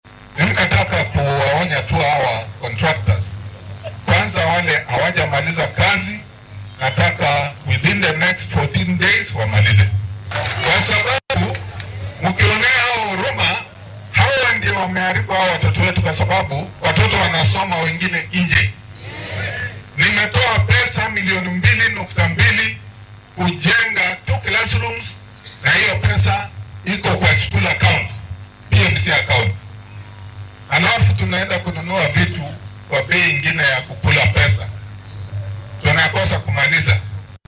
Xildhibaanka laga soo doortay deegaanka Waqooyiga Teso ee ismaamulka ee ismaamulka Busia, Oku Kaunya ayaa u soo saaray 14 maalmood oo kama dambays ah qandaraaslayaasha ka masuulka ah mashaariicda horumarineed ee xayirmay ee deegaankiisa. Isagoo hadlayay intii lagu guda jiray howlgelinta mashruuc maktabadeed oo ay ku baxday 3.5 milyan ayuu xildhibaanka ka digay in cidii u hoggaansami waaydo ay la kulmi doonto tallaabo sharci ah.